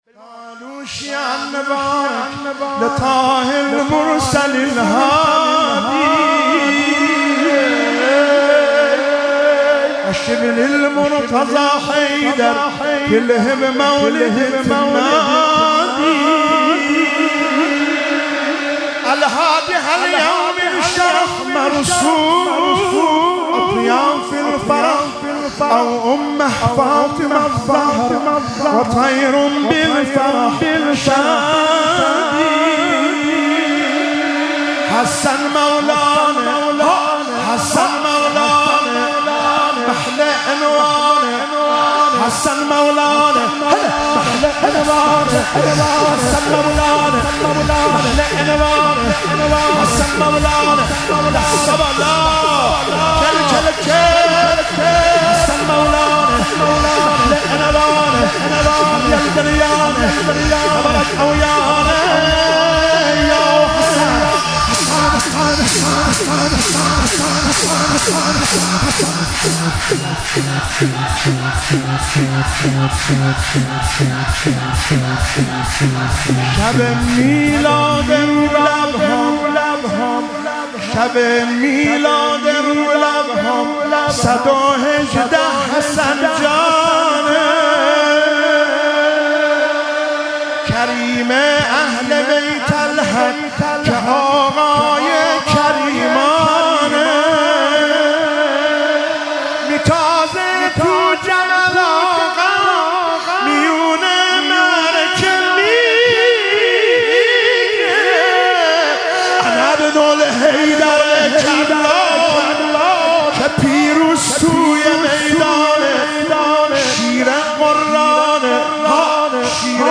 شور- یزله خوانی
مراسم مناجات خوانی شب شانزدهم و جشن ولادت امام حسن مجتبی علیه السلام ماه رمضان 1444